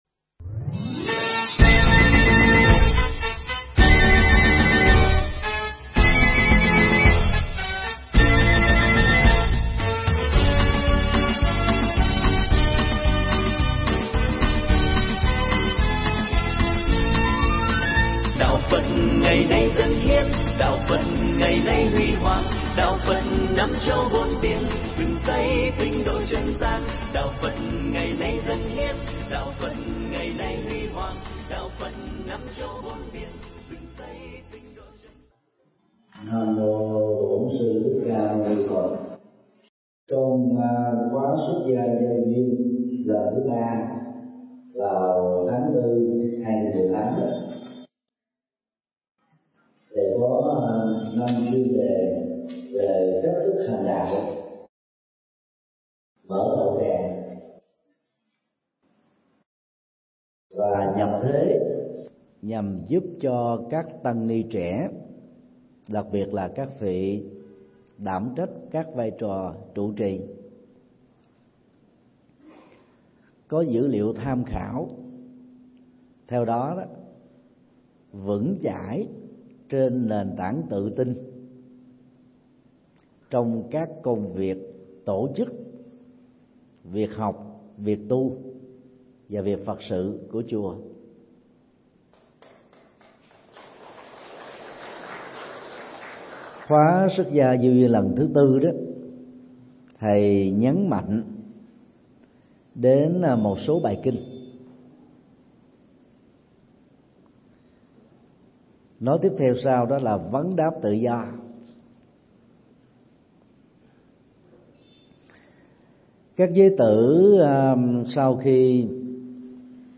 Mp3 Pháp Thoại Học Hạnh Từ Bi Của Đức Phật
giảng trong khóa tu Xuất Gia Gieo Duyên 4 tại chùa Giác Ngộ